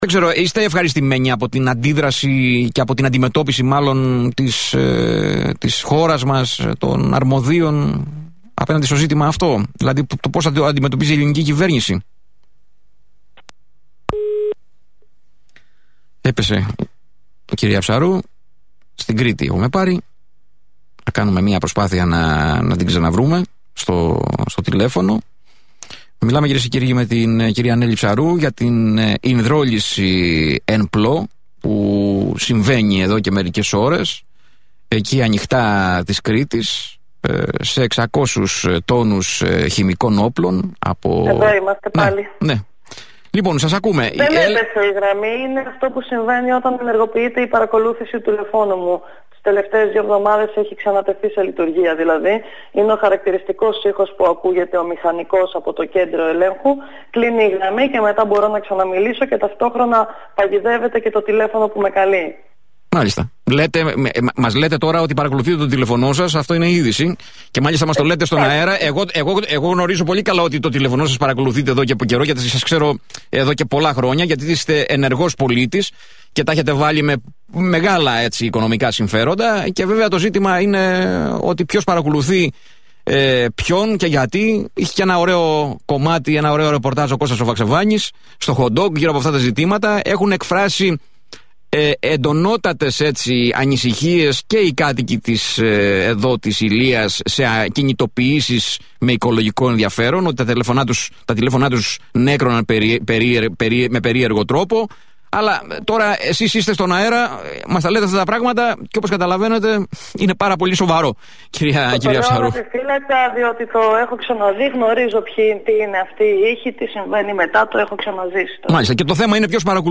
ΑΚΟΥΣΤΕ ΕΔΩ ΤΟ ΗΧΗΤΙΚΟ ΝΤΟΚΟΥΜΕΝΤΟ :